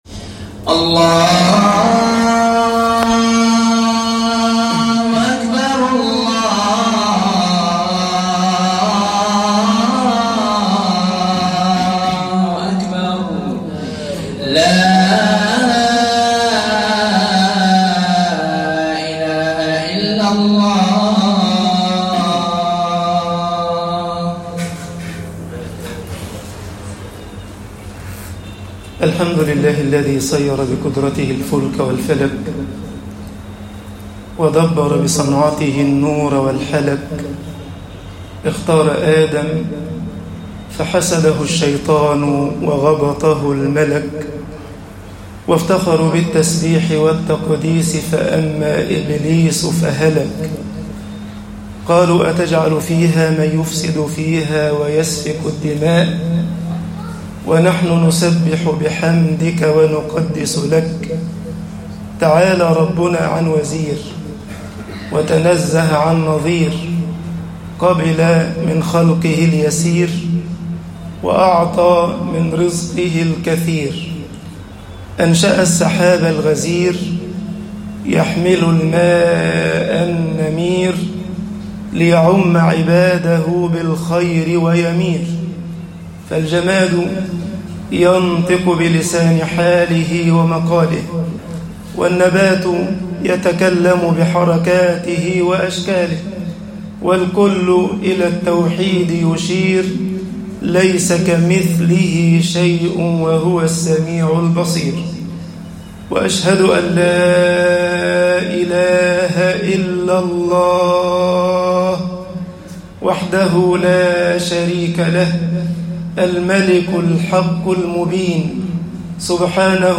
خطب الجمعة - مصر العِنَادُ مانِعٌ من الهداية طباعة البريد الإلكتروني التفاصيل كتب بواسطة